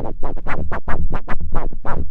LOOSE RHYTHM.wav